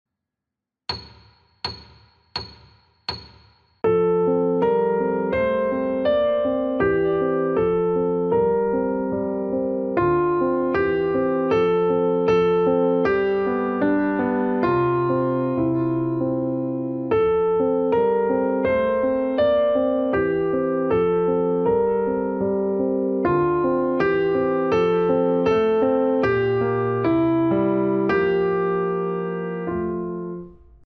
19-Petit-Piece-vysledek-metronom.mp3